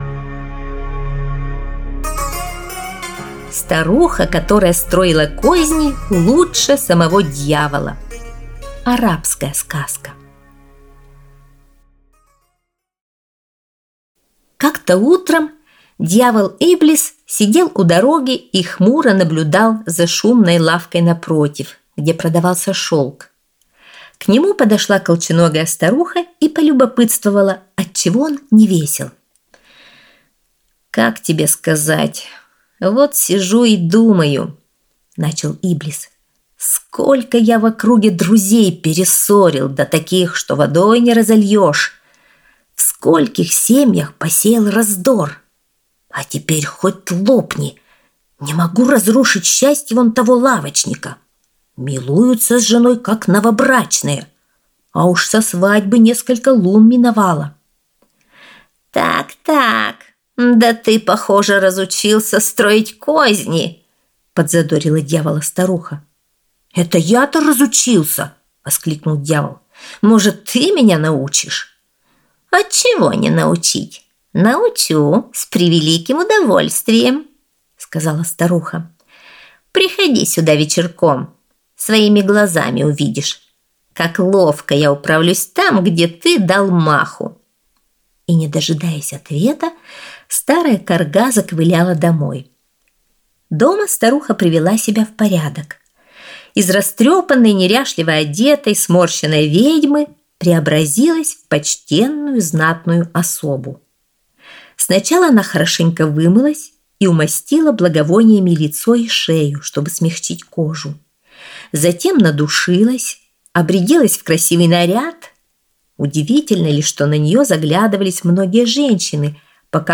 Старуха, которая строила козни лучше самого дьявола - арабская аудиосказка